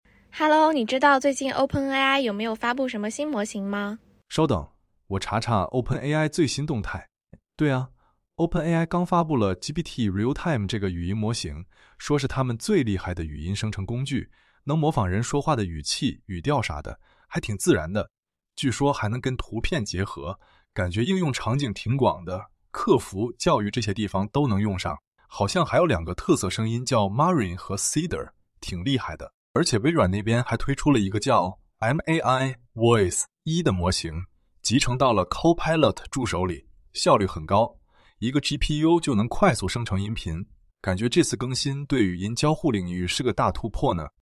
用户也可以选择联网模式，让Step-Audio 2 mini进行实时搜索，并且用语音输出。